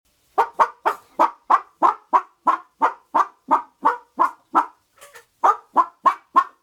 Chicken Clucking Type 2